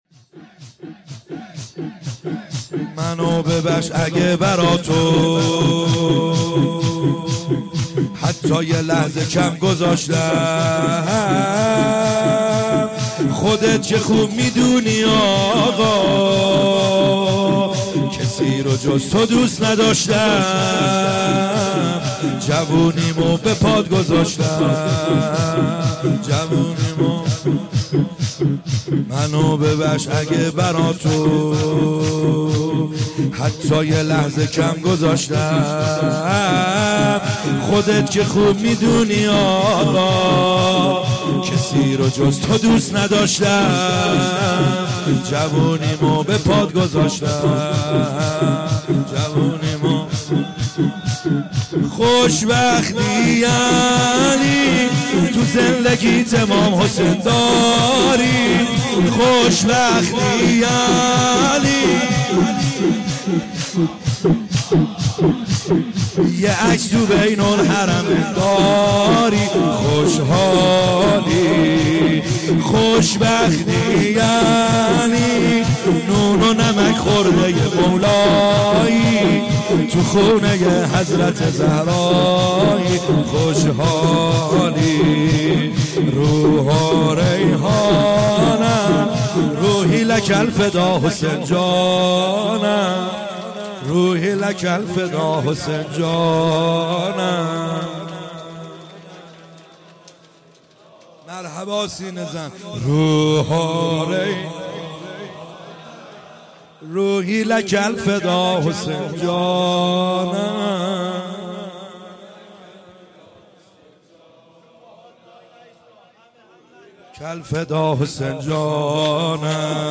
خیمه گاه - بیرق معظم محبین حضرت صاحب الزمان(عج) - شور | منو ببخش اگه برا تو